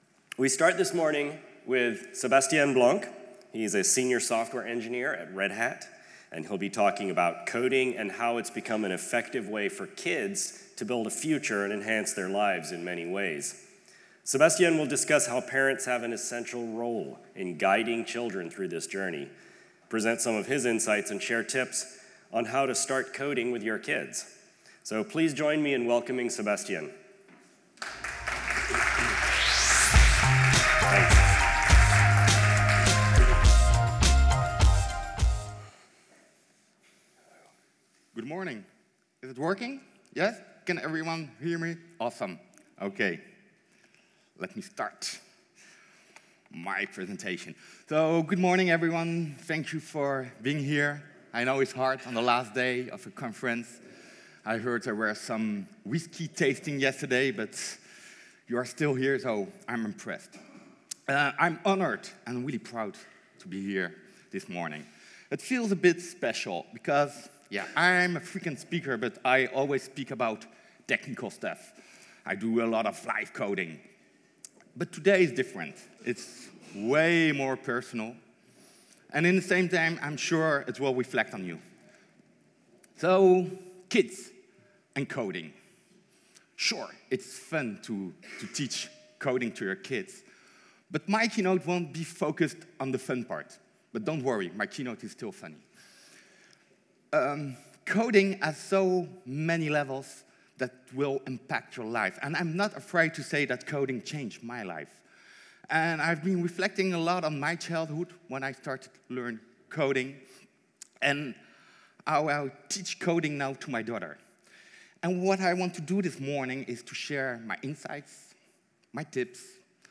ApacheCon Seville 2016
Keynote